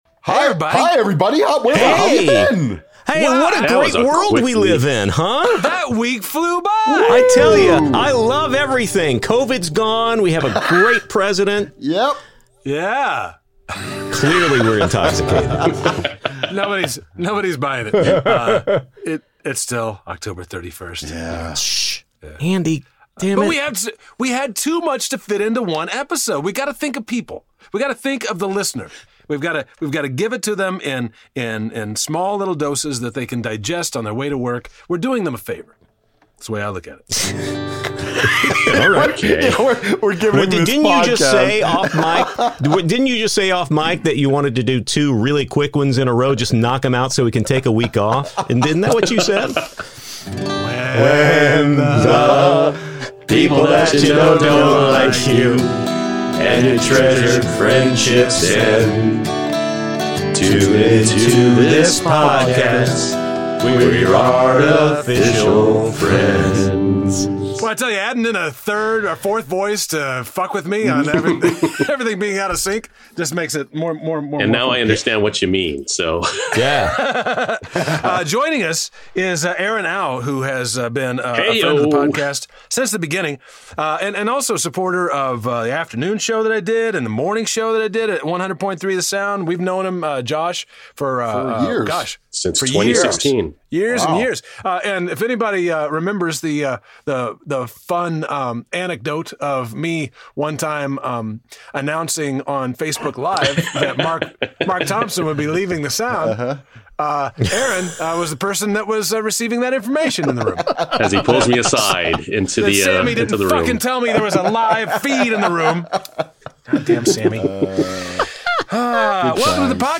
It's the big wrap-upperoo of our 200th episode! It's still October 31st, we have no idea what the next 12 days have in store for us, and we blather on like tipsy idiots in this continued beer-pisode.